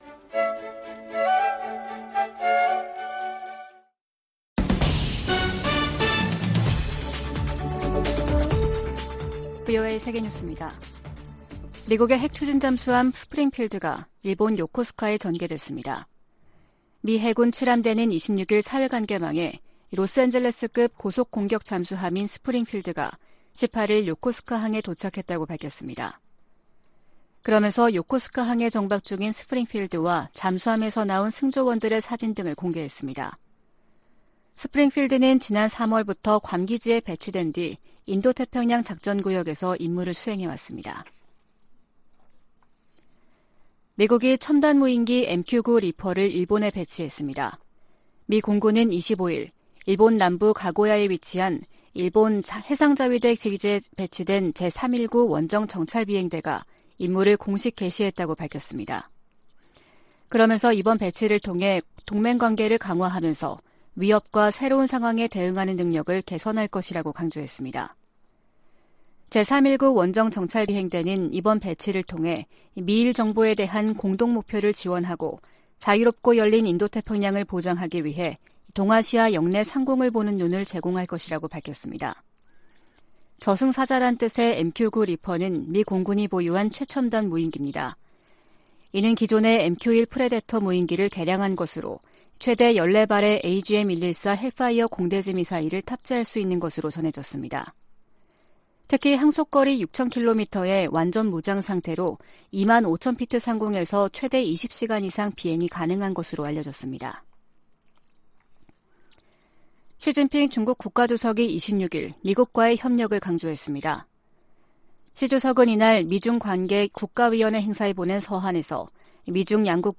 VOA 한국어 아침 뉴스 프로그램 '워싱턴 뉴스 광장' 2022년 10월 28일 방송입니다. 북한이 7차 핵실험을 감행할 경우 연합훈련과 추가 제재 등 다양한 대응 방안이 있다고 미 국무부가 밝혔습니다. 한국 국가정보원은 북한이 미국의 11월 중간선거 이전까지 7차 핵실험을 할 가능성이 있다는 기존의 정보분석을 거듭 제시했습니다. 2023 회계연도 국방수권법안에 대한 미국 상원 본회의 심의가 시작됐습니다.